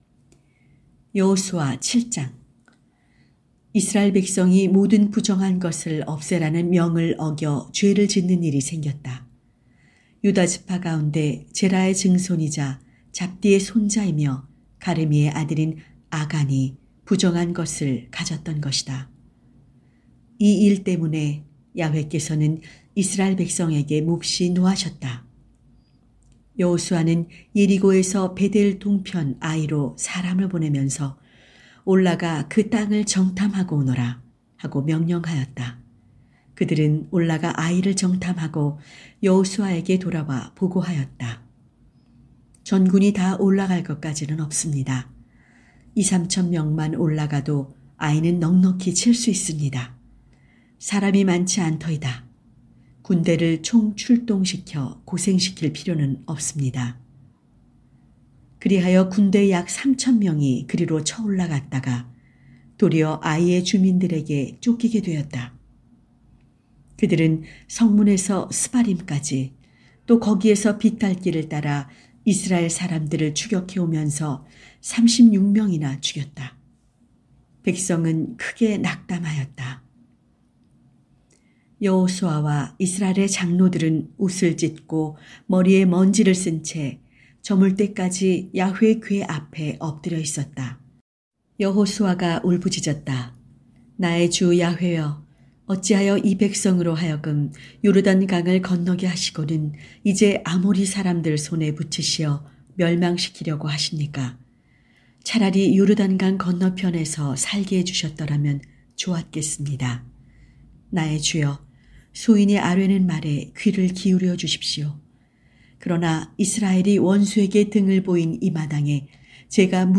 성경 오디오